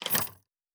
Locker 9.wav